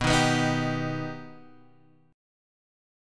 B+ chord